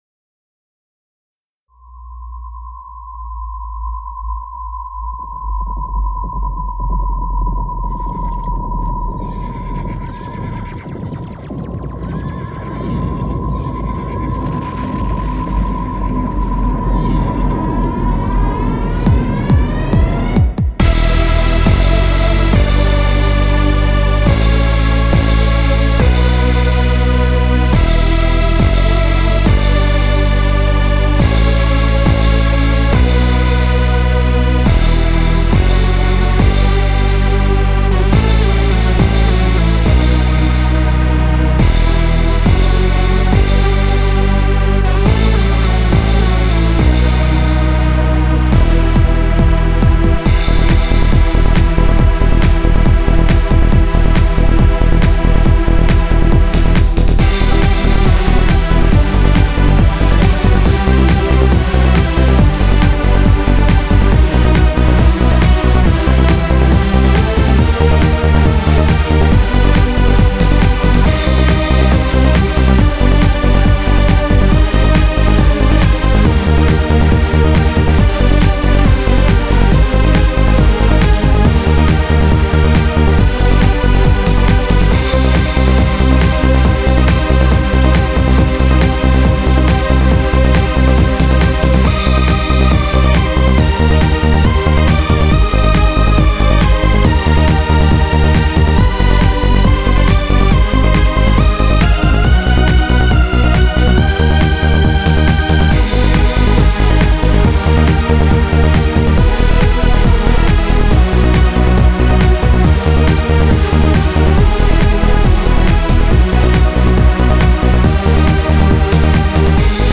Téléchargez le backing track (bande-son en mp3):
Ecoutez le backing track :